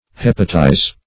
Hepatize \Hep"a*tize\, v. t. [imp.